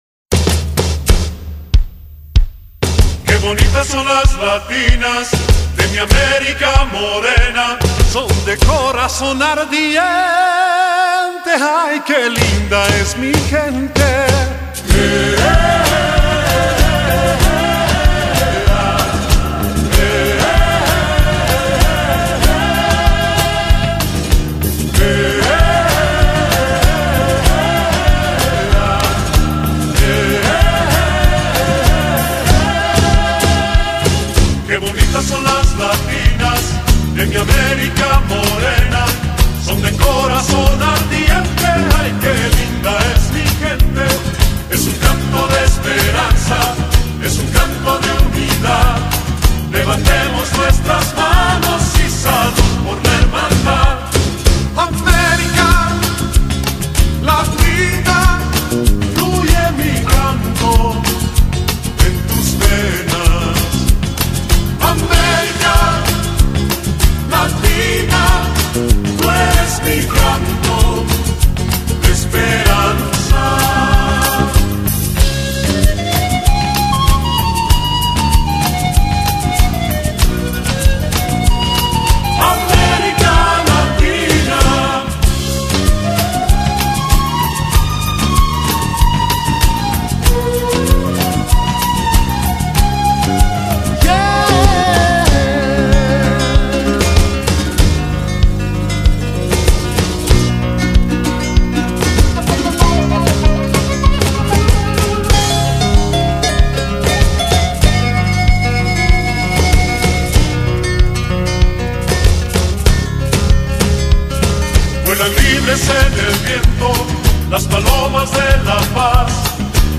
他們的音樂中總是充滿著爽朗分明的熱情節奏，以及那輕快悅耳的柔美音符，原汁原味的異國調性，令人印象深刻、愛不釋手。